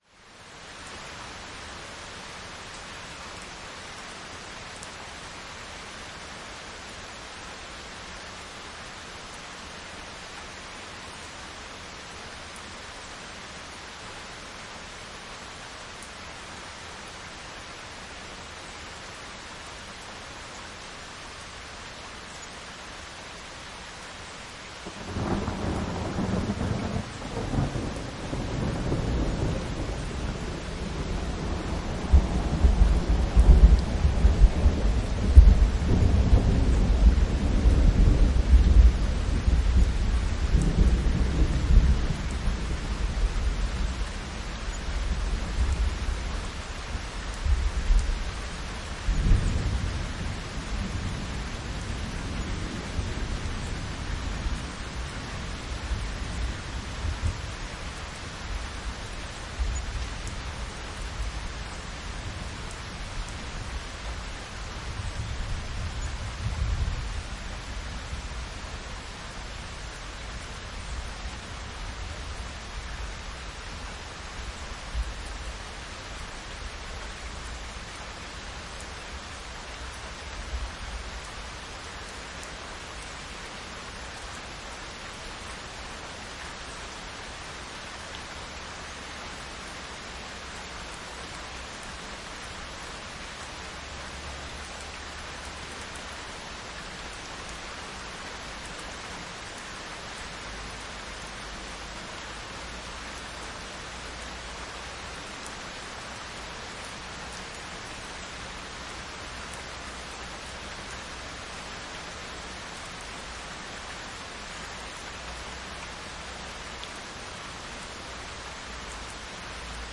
雷雨1
描述：14年10月突然发生暴雨。闪电击中了我的头顶。使用MS胶囊记录在Zoom H6上，然后在带有臭氧6的Protools中进行eq和压缩
Tag: 风暴 气候 雷电 暴雨 雷暴 自然 现场录音